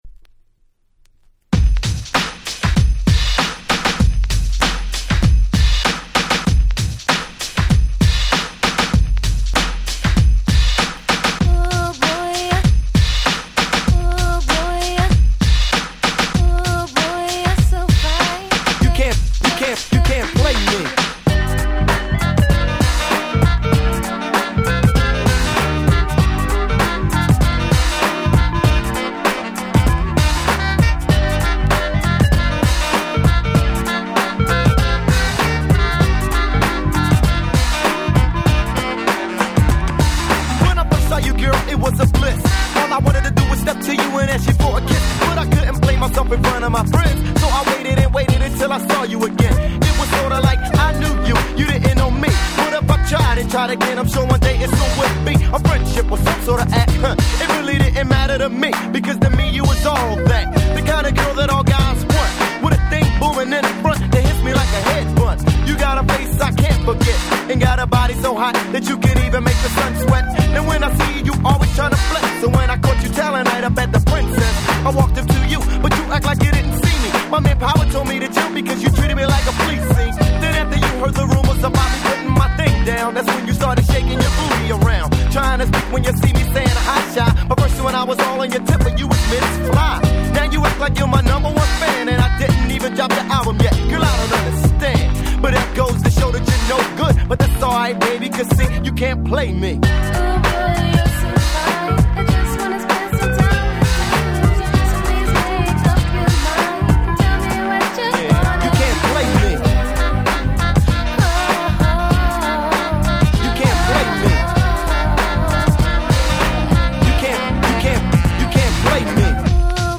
安心して下さい、こちらはバッチリ繋ぎ易く加工されてますよ〜！(笑)